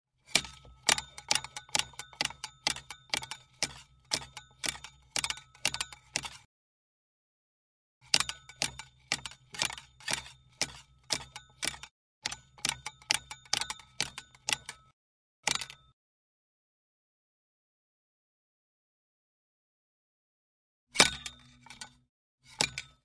合成器 " 卡通弹跳合成器音高弯曲
描述：在模拟单声道合成器上录制的音高弯曲声音模拟经典视频游戏和动画中的“圆形”声音。
标签： 弹跳 卡通 可爱
声道立体声